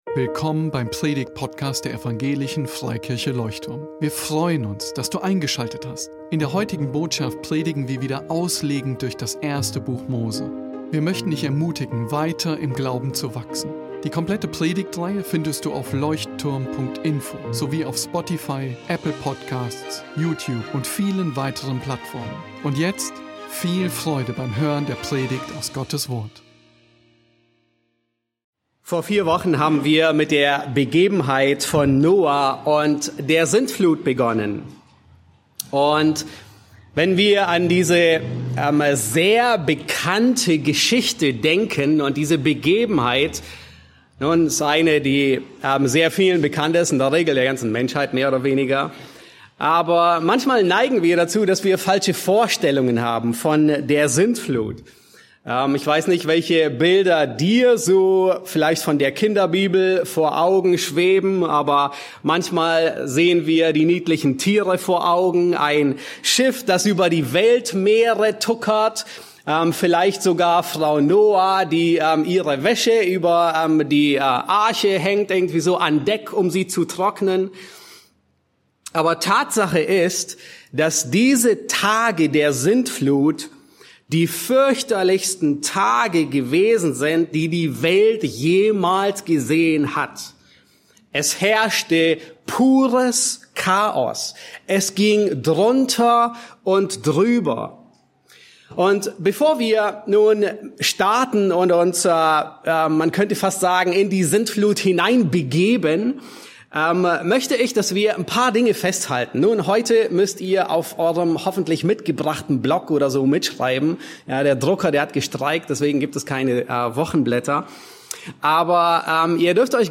In dieser Predigt aus der Reihe zum 1. Buch Mose tauchen wir in den biblischen Bericht über die Sintflut ein und entdecken, warum sie ein „Schuss vor den Bug“ – ein göttlicher Warnschuss – für die gesamte Menschheit war. Die Predigt beleuchtet die Ursachen der Sintflut und die Rolle der Bosheit des Menschen.